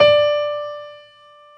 55p-pno25-D4.wav